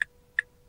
Index of /fastdownload/r_speedo/files/blinkers